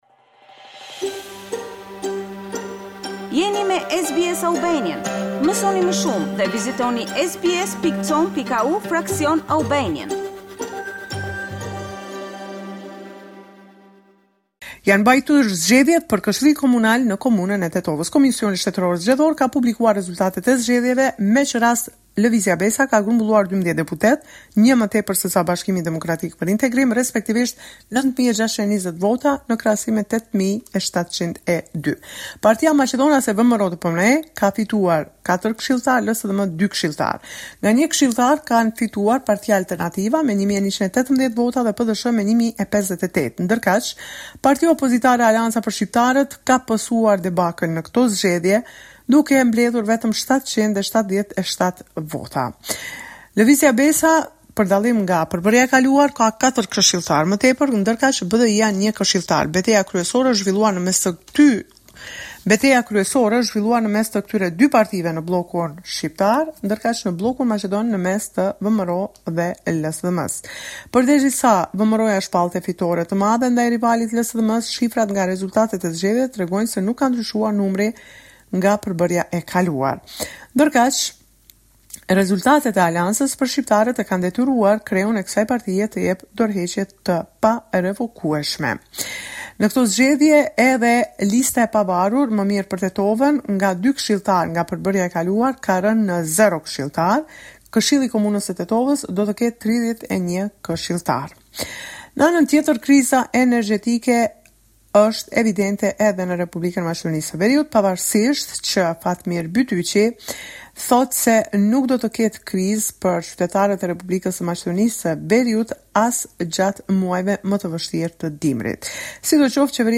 This is a report summarizing the latest developments in news and current affairs in North Macedonia.